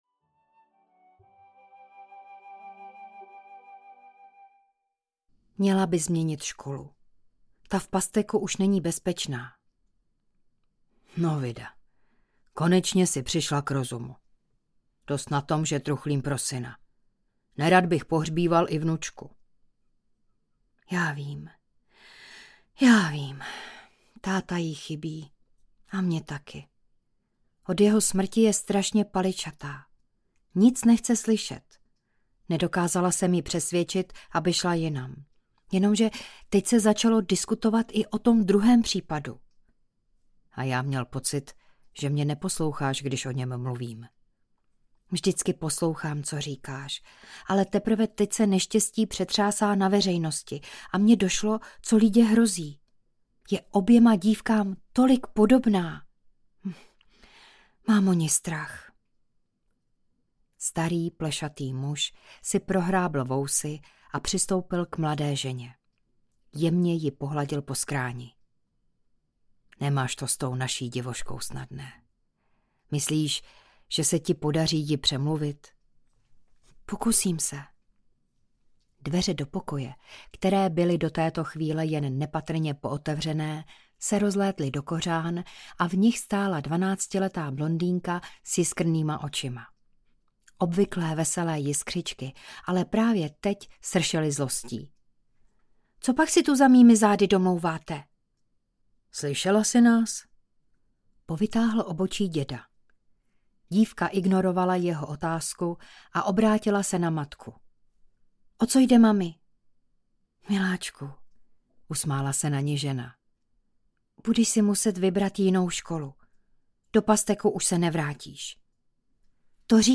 Dračí cejch audiokniha
Ukázka z knihy
draci-cejch-audiokniha